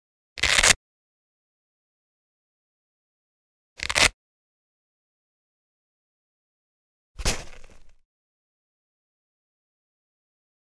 household
Computer Laptop Case Soft Cover Open Velcro Flap